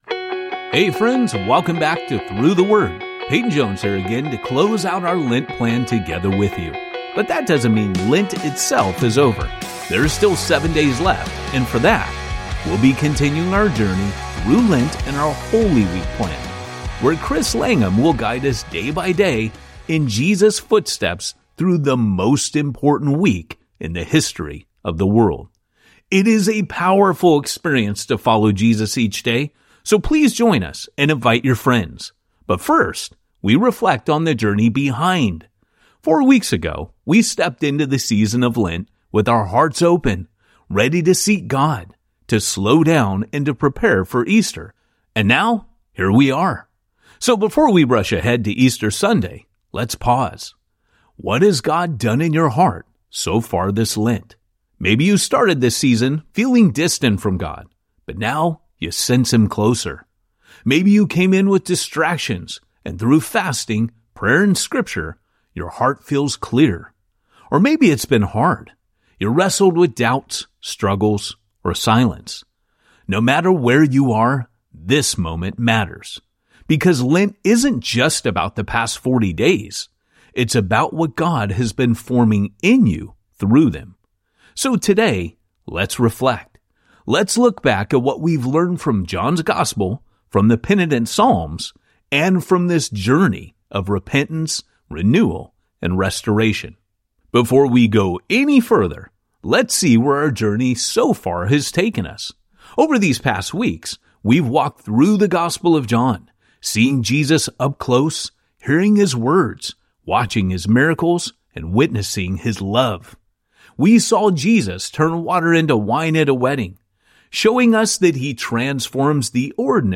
The story comes alive each day as Through the Word’s ten-minute audio guides walk you through each chapter with clear explanation and engaging storytelling.